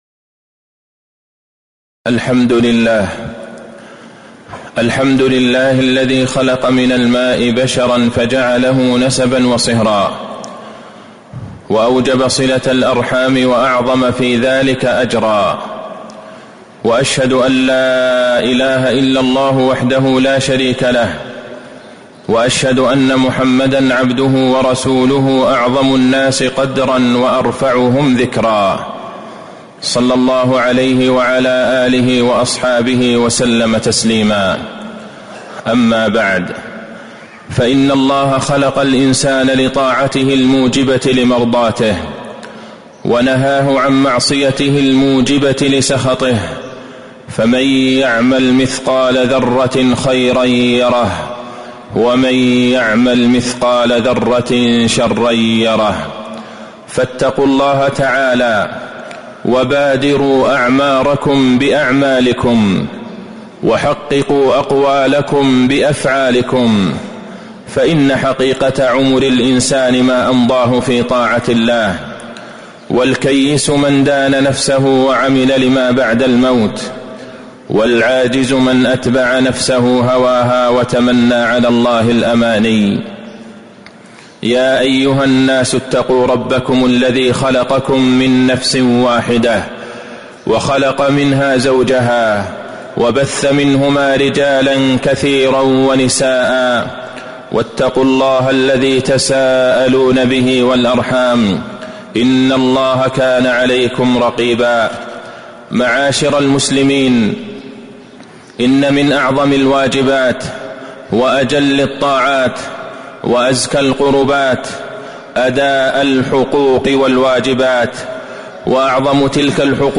تاريخ النشر ٣٠ صفر ١٤٤٥ هـ المكان: المسجد النبوي الشيخ: فضيلة الشيخ د. عبدالله بن عبدالرحمن البعيجان فضيلة الشيخ د. عبدالله بن عبدالرحمن البعيجان حقوق ذوي القرابة والأرحام